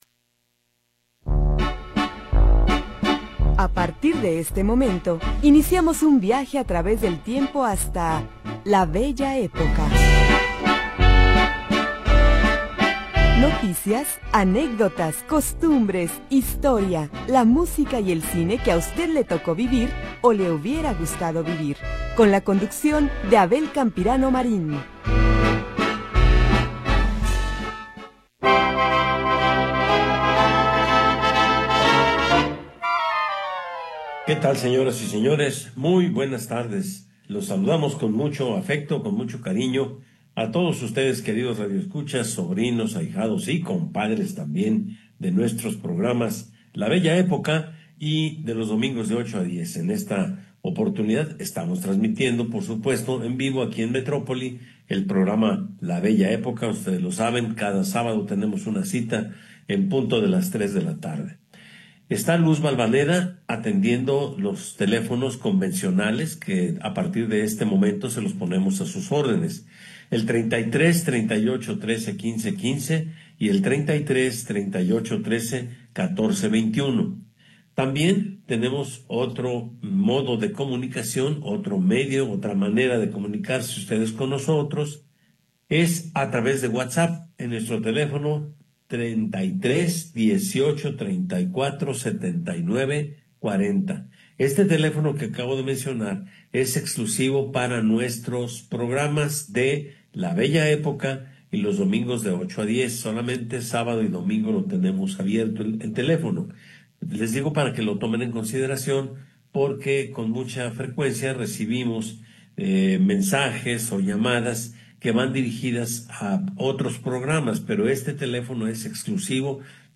Programa transmitido el 17 de Enero de 2026.